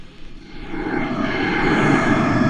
ANIMALNOIS.wav